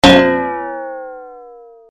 音效-尴尬.mp3